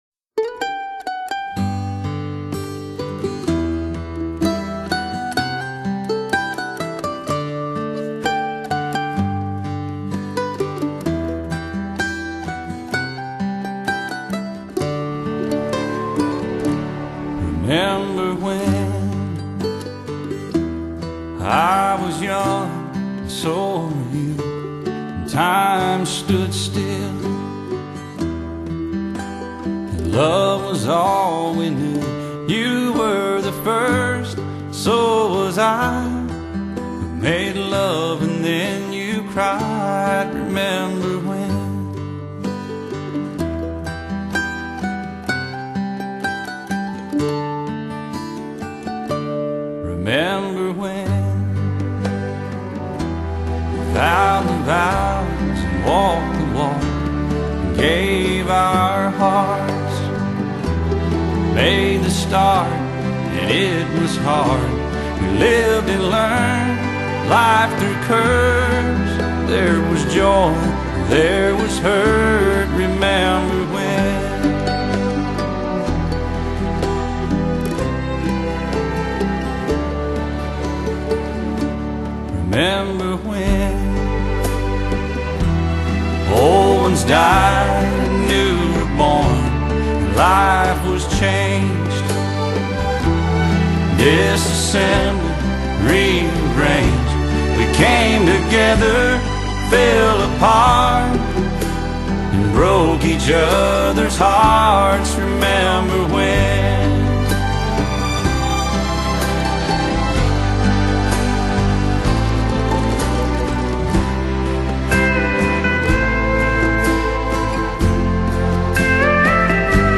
حس میکنم مایه های شرقی توش داره بسیار زیبا🌷